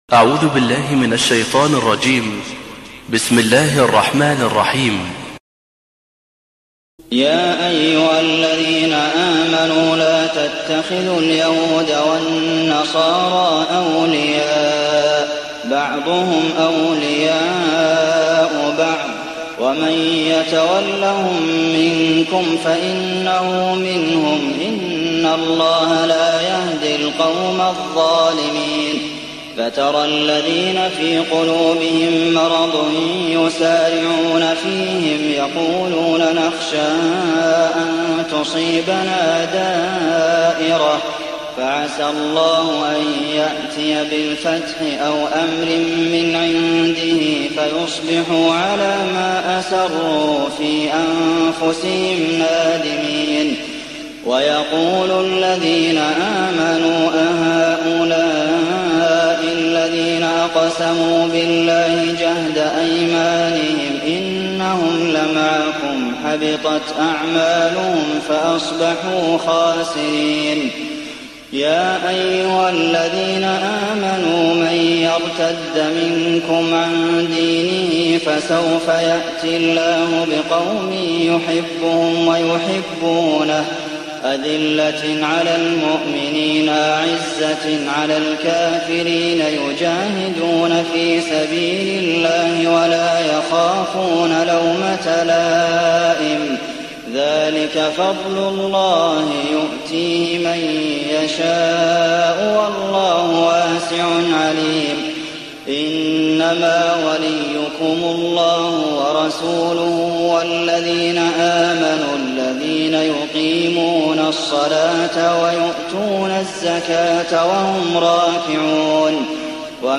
تهجد ليلة 25 رمضان 1419هـ من سورة المائدة (51-109) Tahajjud 25th night Ramadan 1419H from Surah AlMa'idah > تراويح الحرم النبوي عام 1419 🕌 > التراويح - تلاوات الحرمين